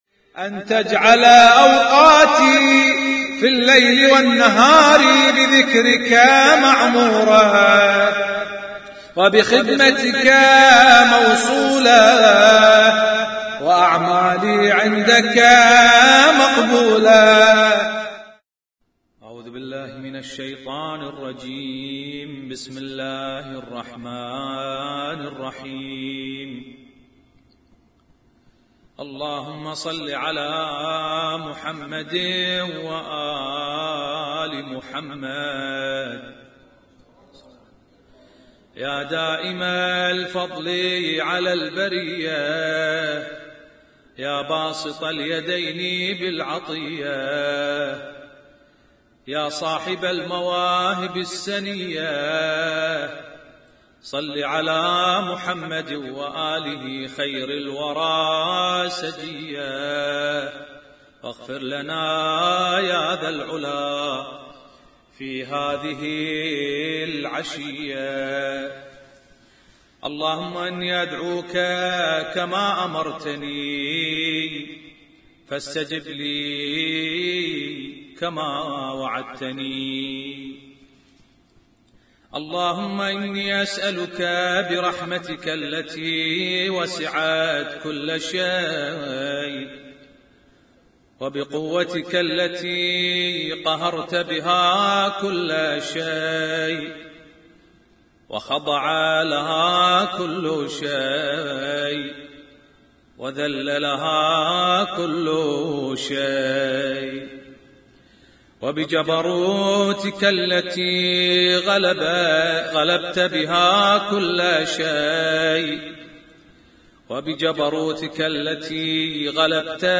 Husainyt Alnoor Rumaithiya Kuwait
اسم التصنيف: المـكتبة الصــوتيه >> الادعية >> دعاء كميل